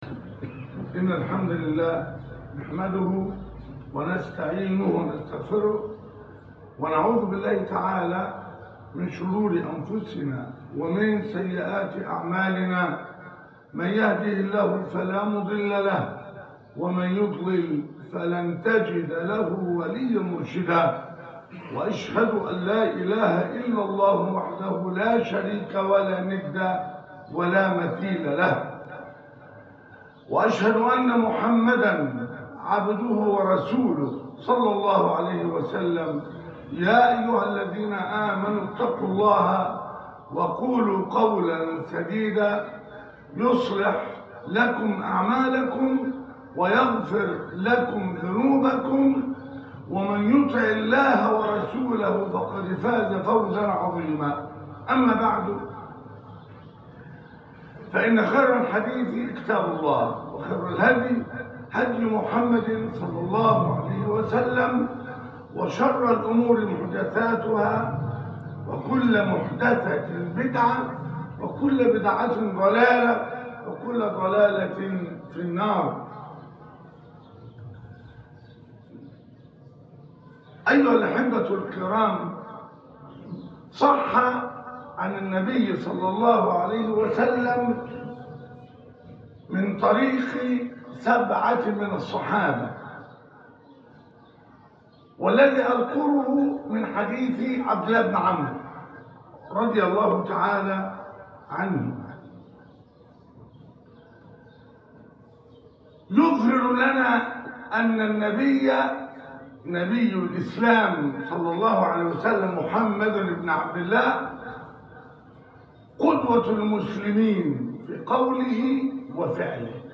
الاستعاذة من أربع - الخطبة الأولى